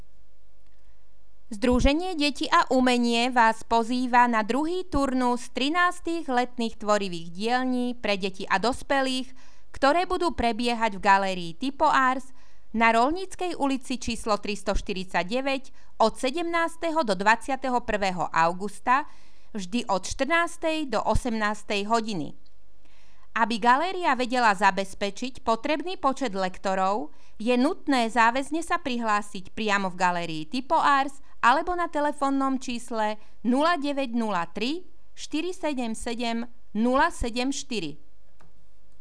Hlásenie rozhlasu
• Hlásenie miestneho rozhalsu k 2. turnusu letných tvorivých dielní  v Galérii Typo&Ars